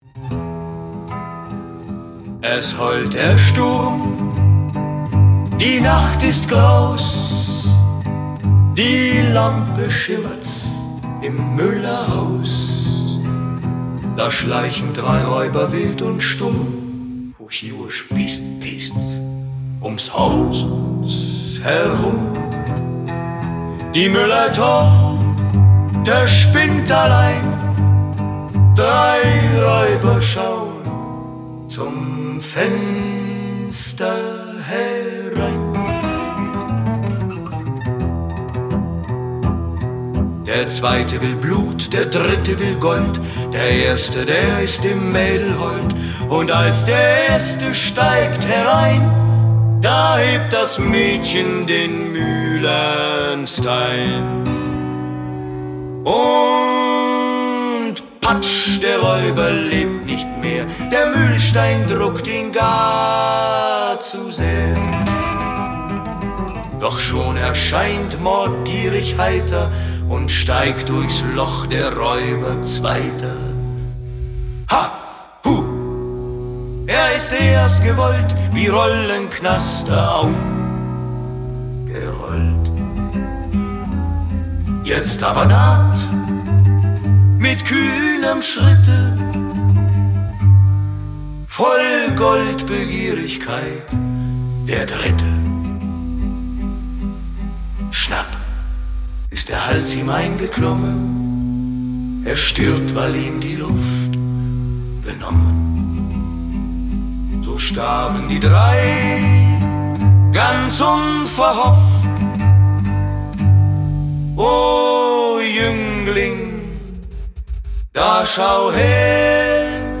Gedichtvertonung
Text: Wilhelm Busch – Musik: mit Hilfe von KI